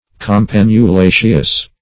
Search Result for " campanulaceous" : The Collaborative International Dictionary of English v.0.48: Campanulaceous \Cam*pan`u*la"ceous\ (k[a^]m*p[a^]n`[-u]*l[=a]"sh[u^]s), a. (Bot.)